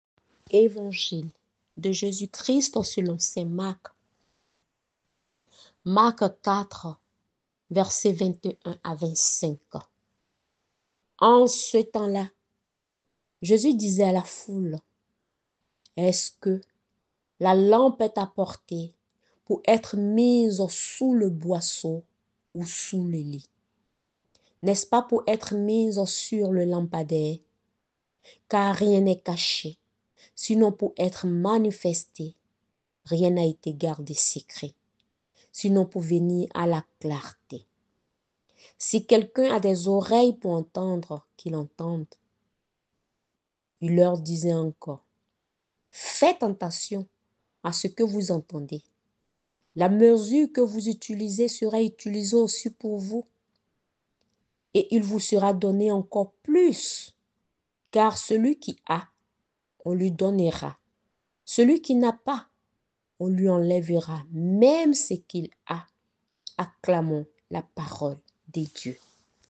ÉVANGILE DU JOUR